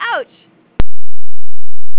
コンピュータが負けたときに出す音
ouch.au